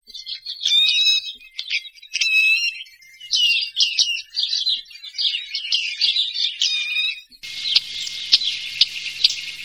Panure à moustaches, panurus biarmicus